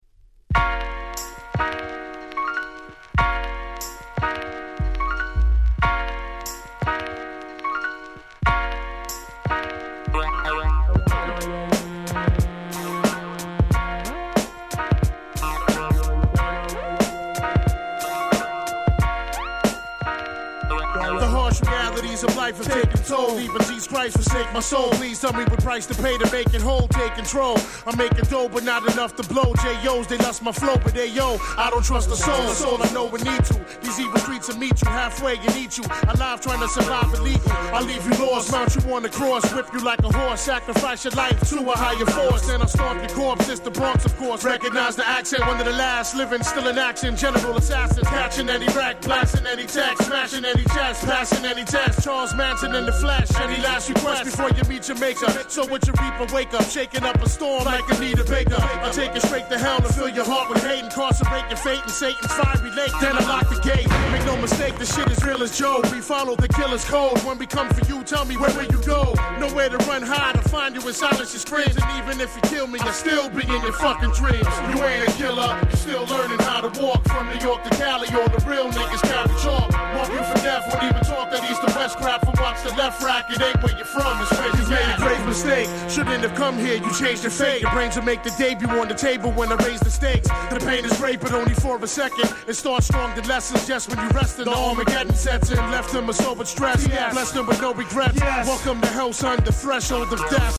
97' Big Hit Hip Hop !!!!